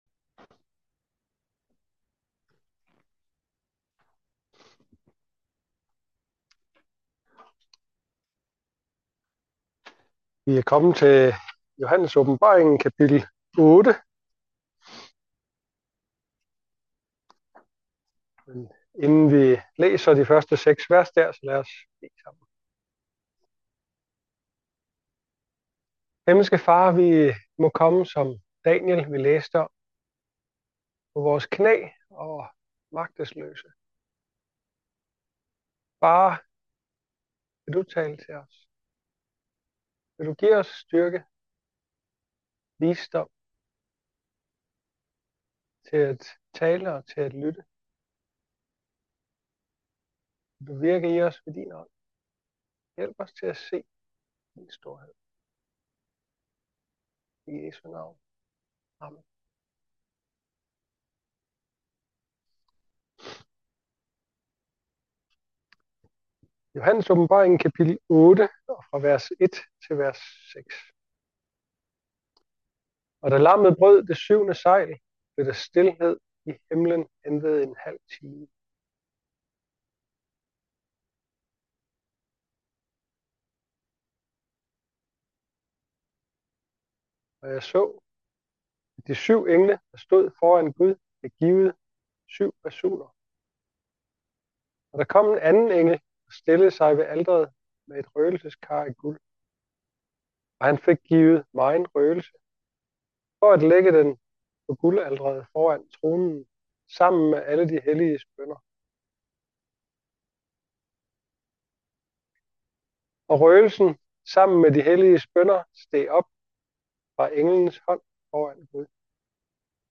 Taler